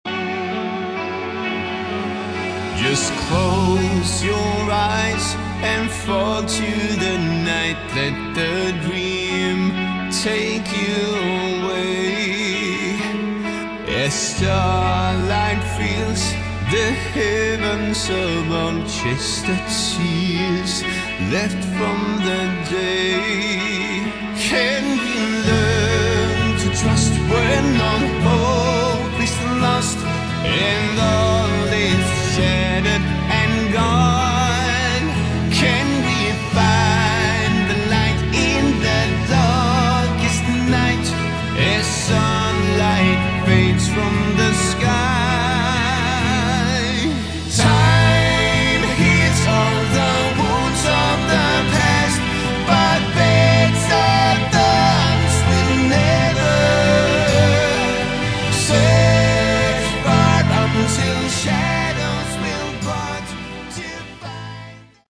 Category: Hard Rock
lead vocals
bass
guitars
drums